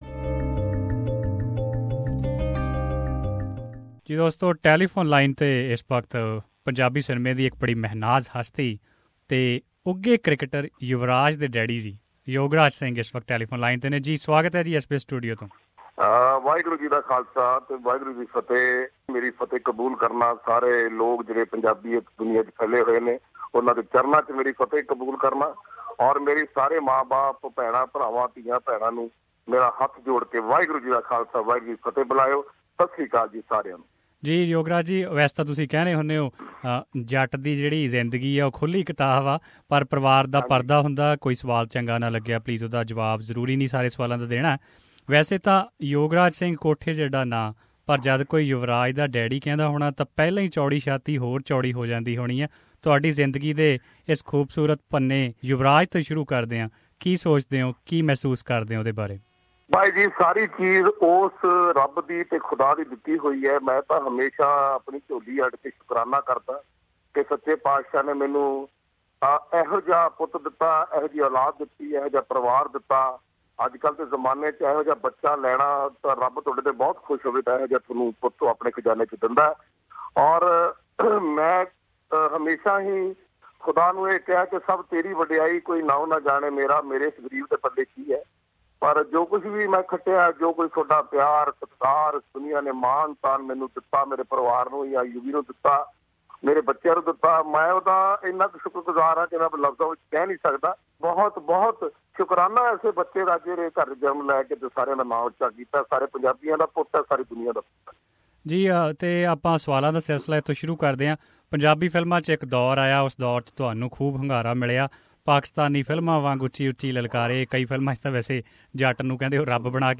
In this interview, Yograj told how unhappy he was after Yuvraj's exclusion from India's 15-member squad for the upcoming World Cup.